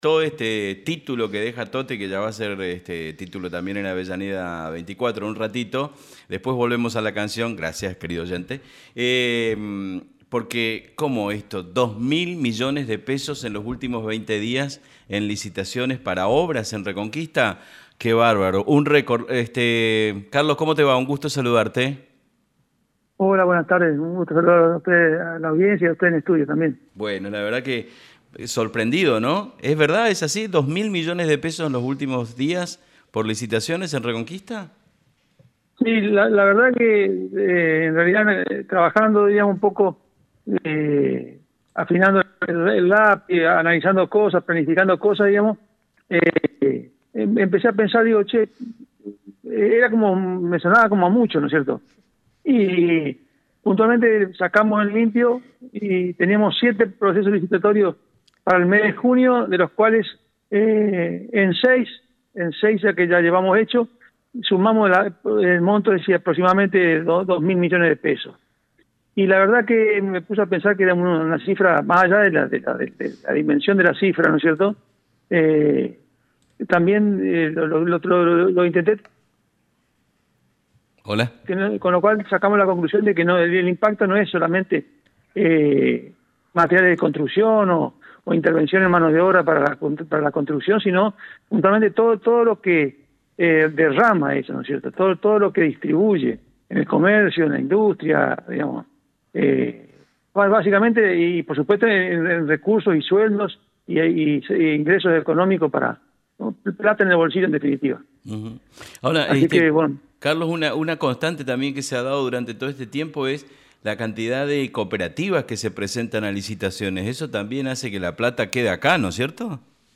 Escucha la nota del secretario de obras públicas, Carlos Castellani: